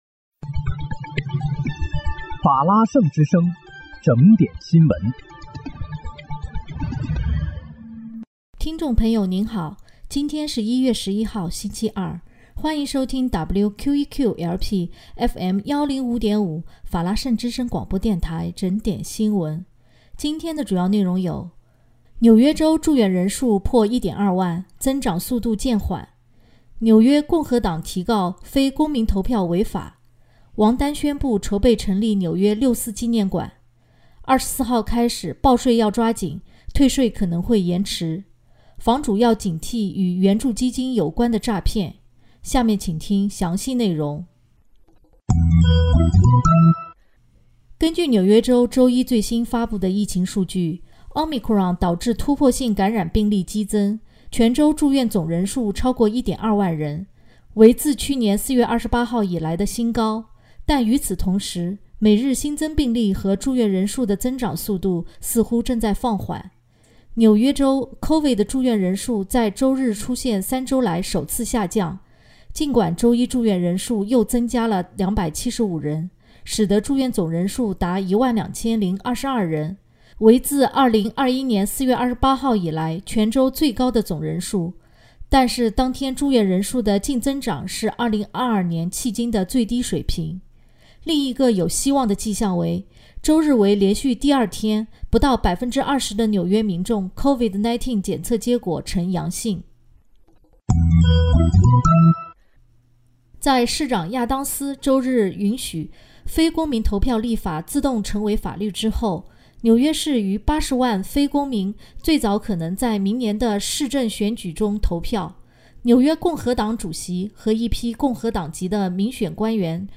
1月11日（星期二）纽约整点新闻